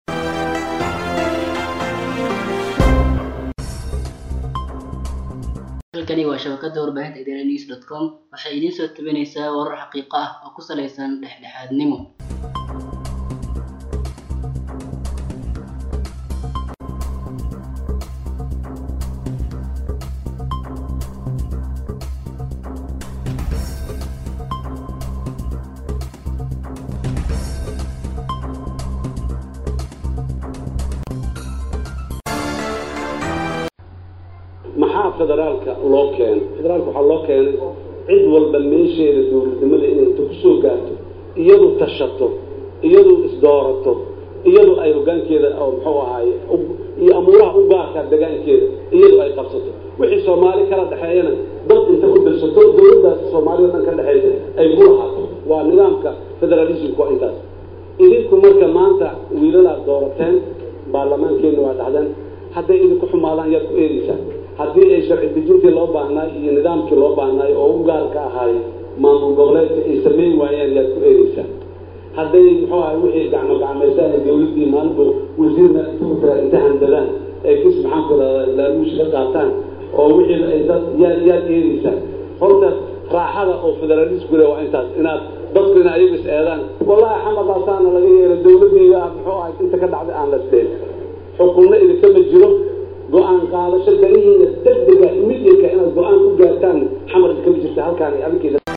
Madaxweyne Xasan SH oo ka hadlayey munaasabadda caleema saarka baarlamaanka Jubbaland ayaa sheegay in Federaalka loo qaatay in dadweynaha Soomaaliyeed aysan eedin dowladda dhexe,wuxuuna farta ku fiiqay in shacabka deegaanadooda dowladda loogu keenay.